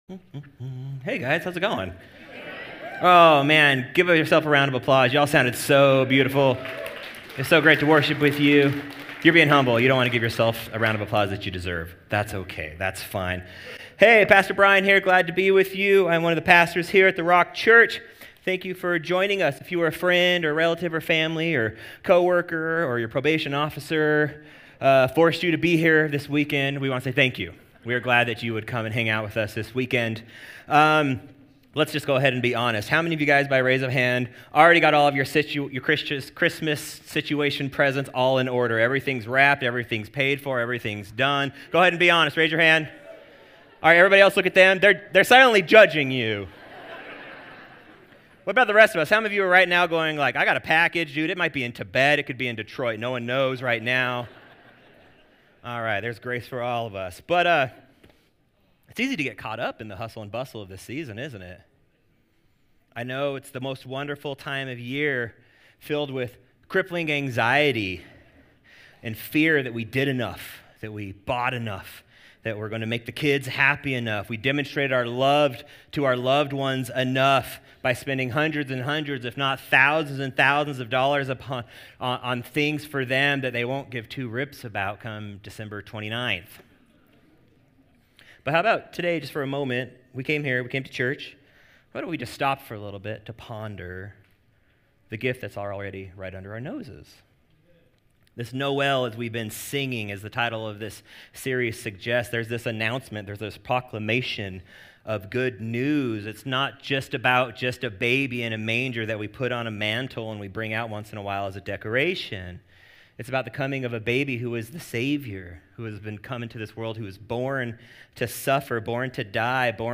A message from the series "Noel."